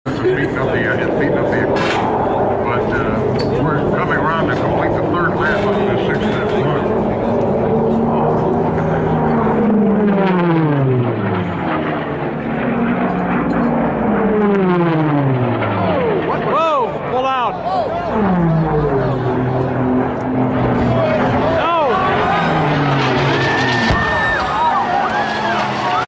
However - if you turn up the sound - on a good system with lots of bass, that "Waaaaaaaaaaaaaaaaaaaaaaaaa PAFFF!" impact sound is a really incredible sound with enormous amounts of data in it.
But that crash - what a sound........... "Waaaaaaaaaaaaaaaaaaaaaaaaa PAFFF!"